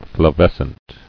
[fla·ves·cent]